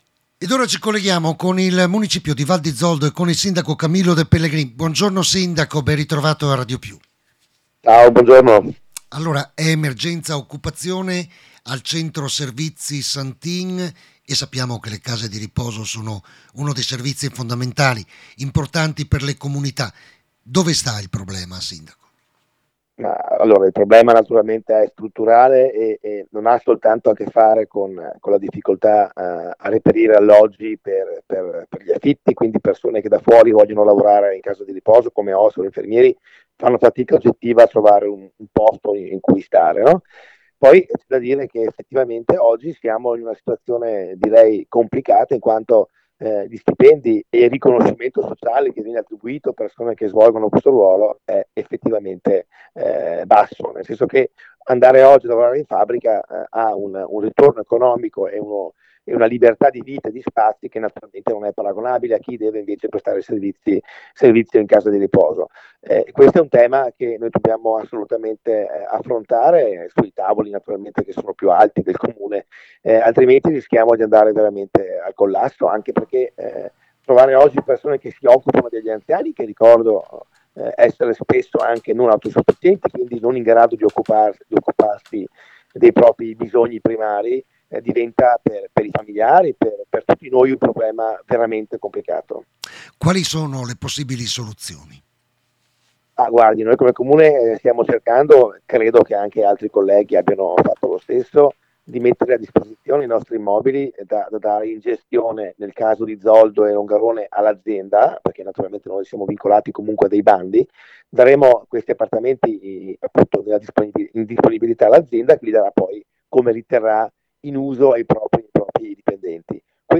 DALLA RASSEGNA STAMPA DELLE 8.30 IL SINDACO CAMILLO DE PELLEGRIN